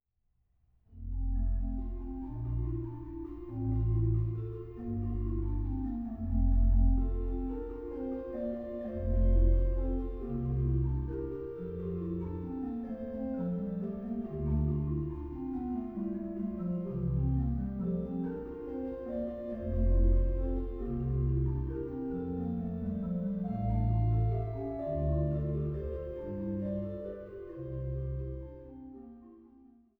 Norddeutscher Orgelbarock